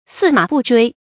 駟馬不追 注音： ㄙㄧˋ ㄇㄚˇ ㄅㄨˋ ㄓㄨㄟ 讀音讀法： 意思解釋： 見「駟馬難追」。